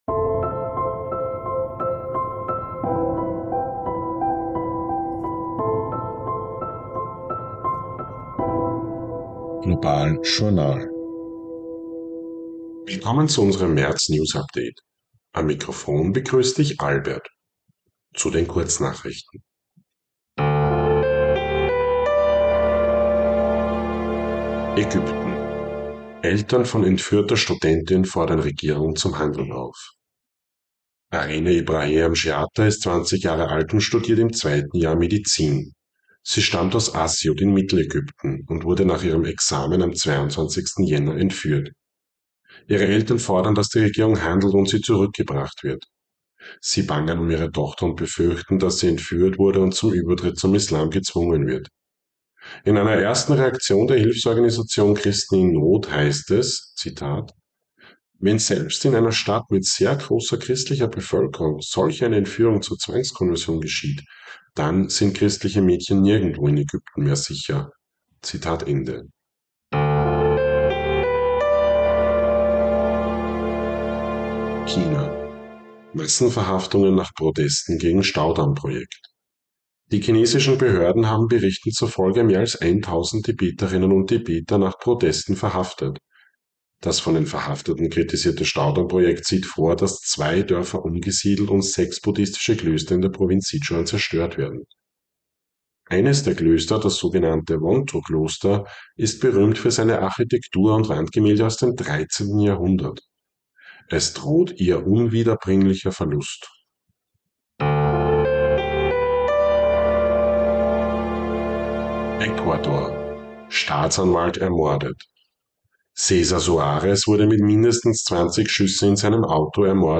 News Update März 2024